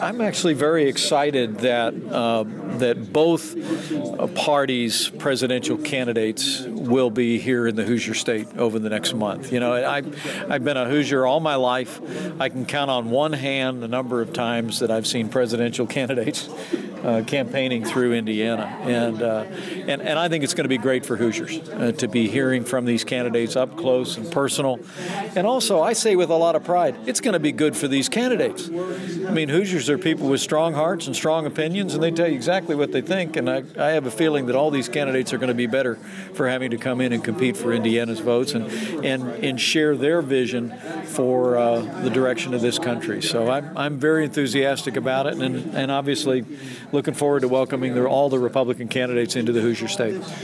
Governor Mike Pence says he is excited to host major presidential candidates in Indiana next month for the primary elections.